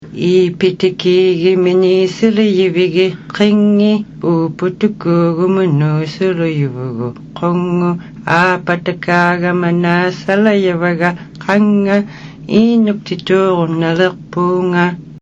Audio is in the South Baffin Dialect.
syllabics-song.mp3